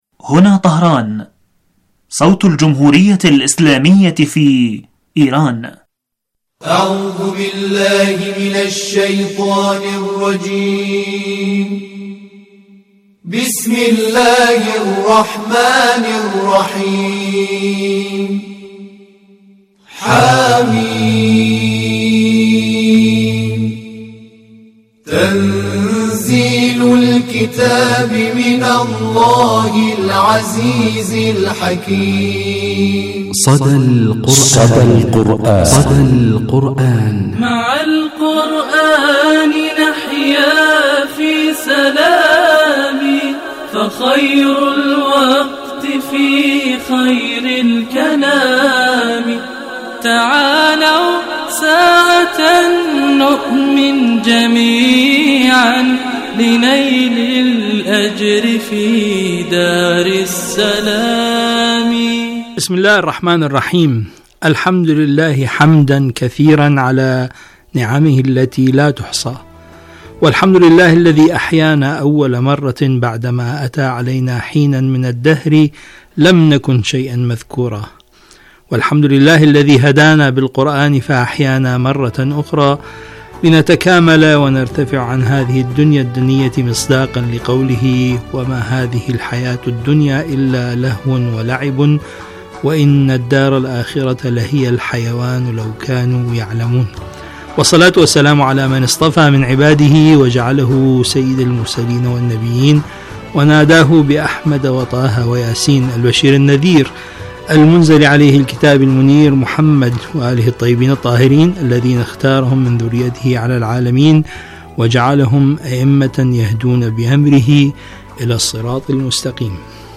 مجلة قرآنية أسبوعية تتناول النشاطات القرآنية ومشاهير القراء من الرعيل الأول ولقاءات وأسئلة قرآنية ومشاركات المستمعين وغيرها من الفقرات المنوعة.